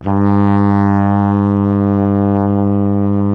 TENORHRN G 0.wav